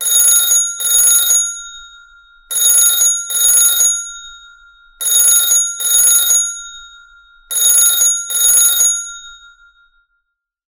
telephone_old_bell_type_ring_002